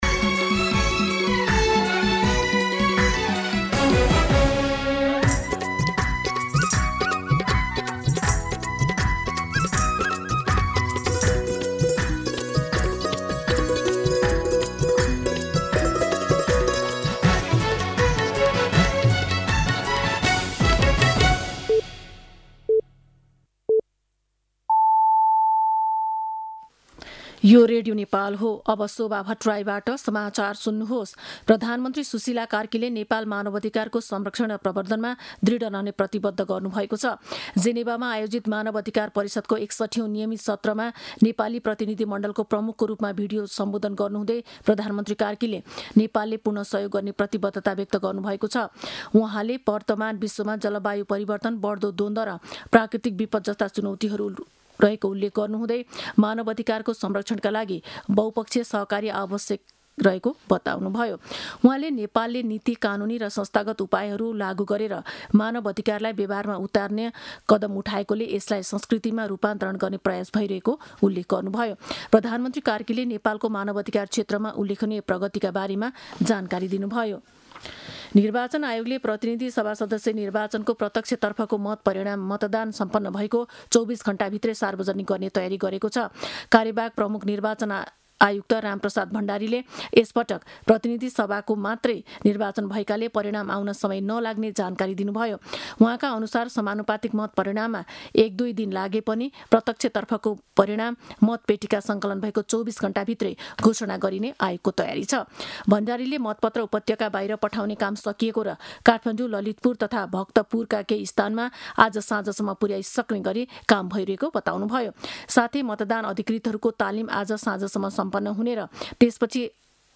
दिउँसो १ बजेको नेपाली समाचार : १२ फागुन , २०८२